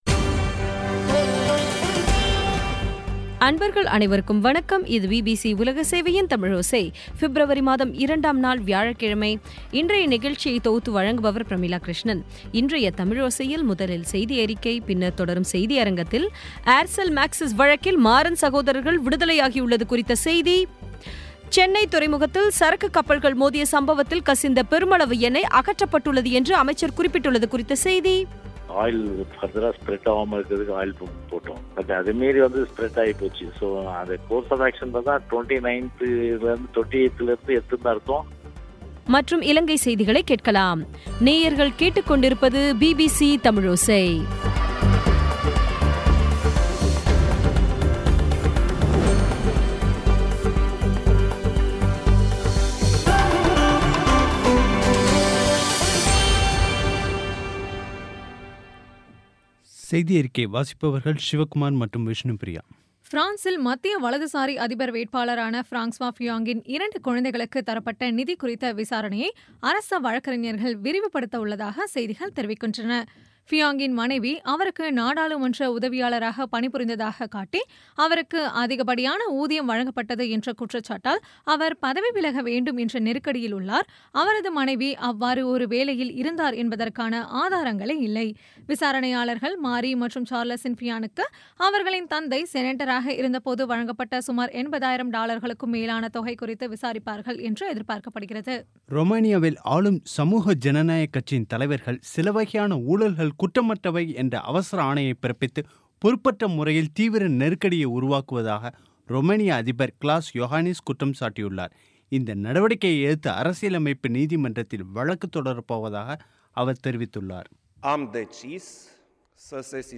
இன்றைய தமிழோசையில், முதலில் செய்தியறிக்கை, பின்னர் தொடரும் செய்தியரங்கத்தில், ஏர்செல் மேக்ஸிஸ் வழக்கில் மாறன் சகோதரர்கள் விடுதலையாகியுள்ளது குறித்த செய்தி சென்னை துறைமுகத்தில் சரக்கு கப்பல்கள் மோதிய சம்பவத்தில் கசிந்த பெருமளவு எண்ணெய் அகற்றப்பட்டுள்ளது குறித்த செய்தி மற்றும் இலங்கை செய்திகளை கேட்கலாம்